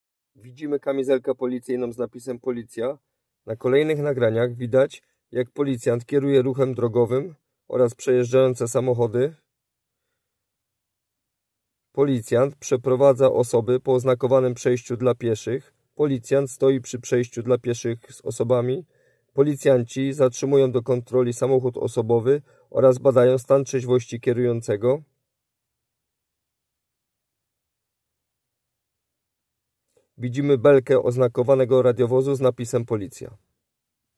Nagranie audio dzialania_wszystkich_swietych-audiodeskrypcja.m4a